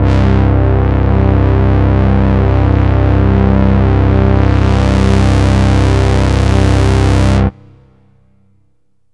SYNTH GENERAL-4 0002.wav